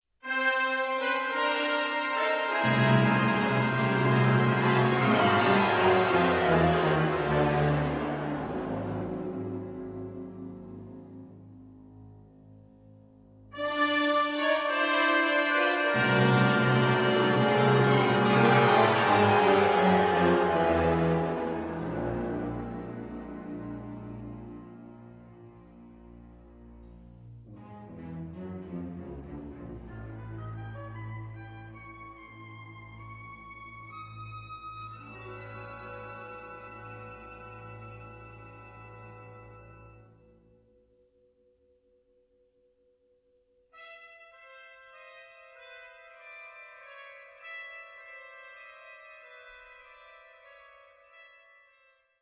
Compositions for Wind Instruments by Spanish Composers